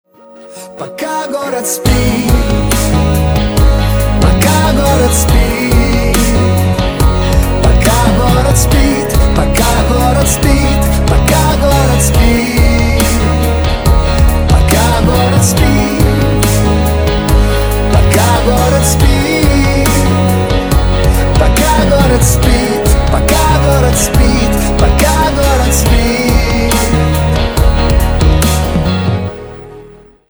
• Качество: 192, Stereo
поп
мужской вокал
громкие
Жанр: христианский рэп